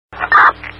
mic_click_self_off.wav